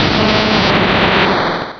Cri de Steelix dans Pokémon Rubis et Saphir.